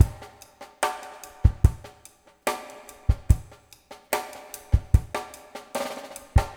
BOL DRMWET-L.wav